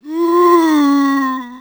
c_zombim4_hit1.wav